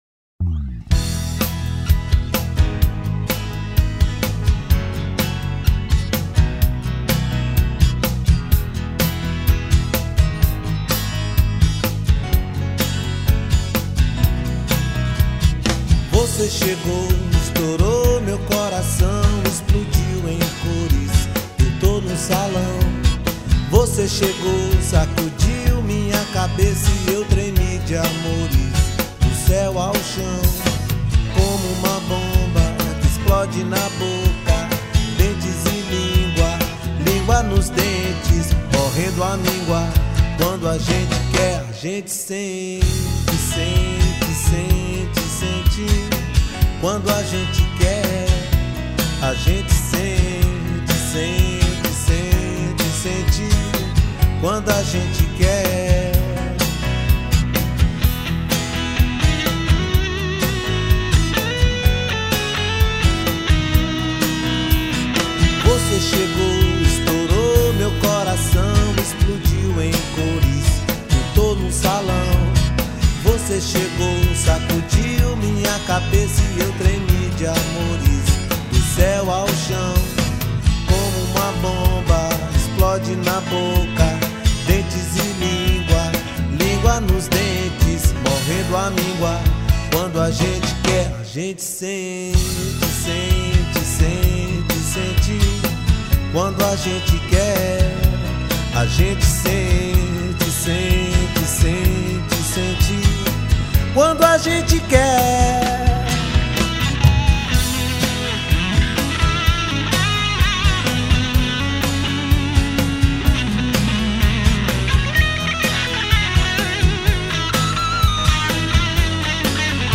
2610   03:17:00   Faixa: 2    Reggae